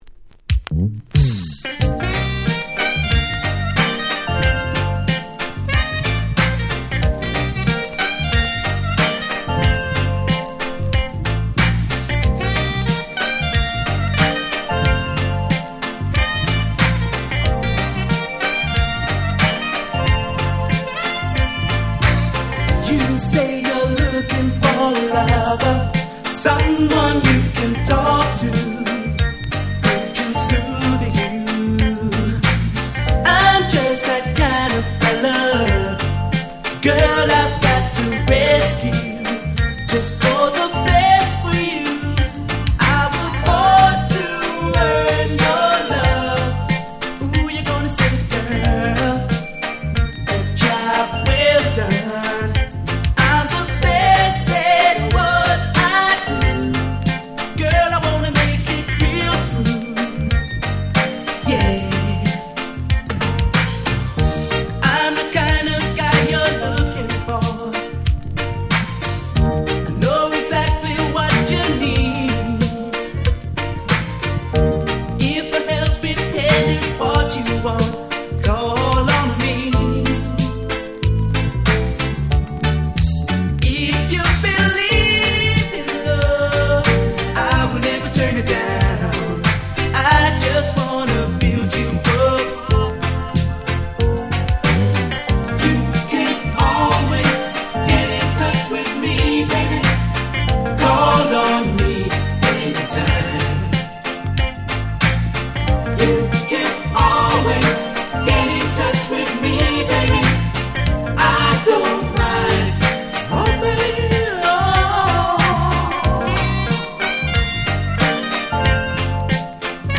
84' Super Nice UK Lovers Reggae !!
ラバーズレゲエ カバー コラージュ Soul ソウル